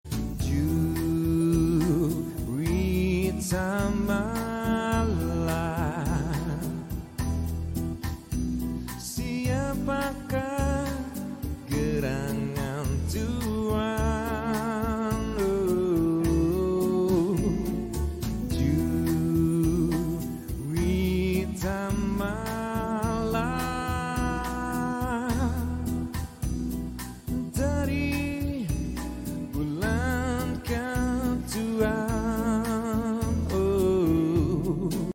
Tes Live Karaoke Menggunakan Mixer